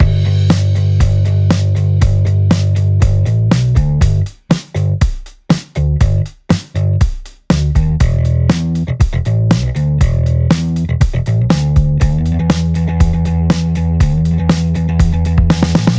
色々なベースラインのリズム
こんな感じで、たとえドラムが同じでも、そこにどんなベースが合わさるかによって印象が大きく変わります。
r1-rhythm-by-bass.mp3